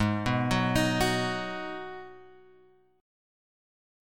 G# Minor 7th